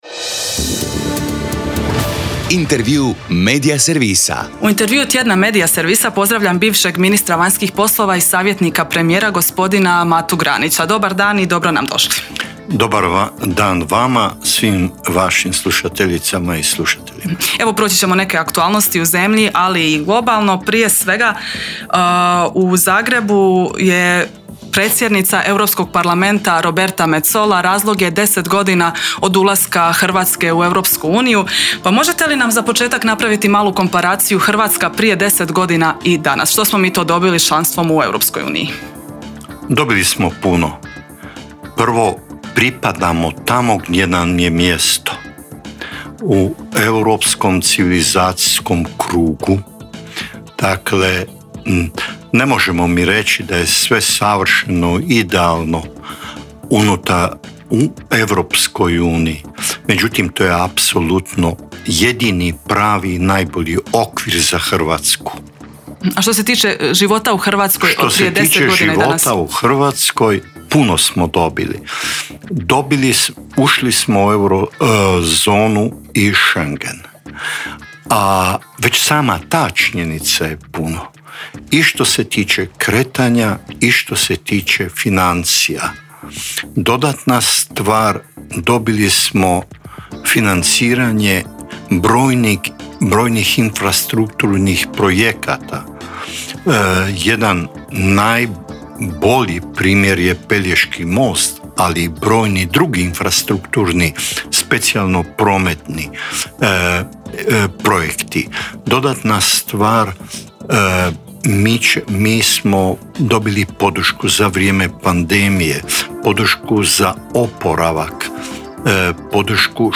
ZAGREB - Povodom 10. godišnjice ulaska Hrvatske u Europsku uniju u Intervjuu tjedna Media servisa ugostili smo savjetnika premijera i bivšeg ministra...